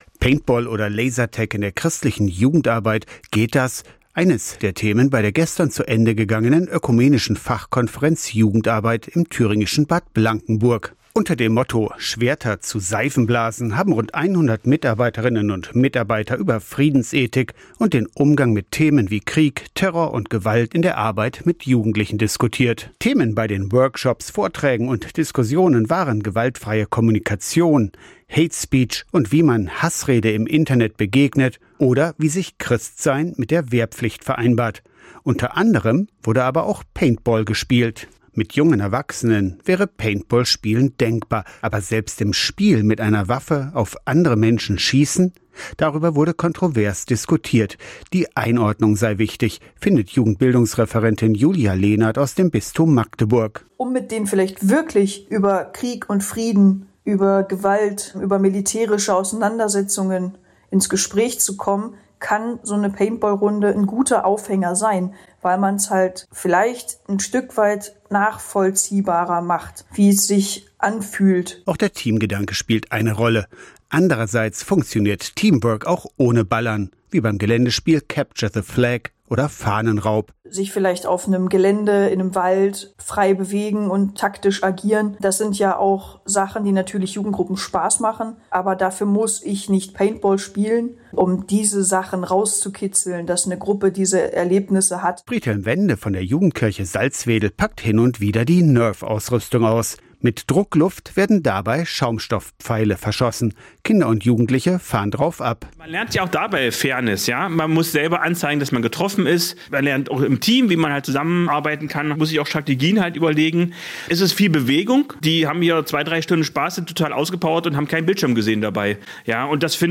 Eines der Themen bei der gestern zu Ende gegangenen ökumenischen Fachkonferenz im thüringischen Bad Blankenburg. Interviewte: